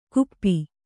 ♪ kuppi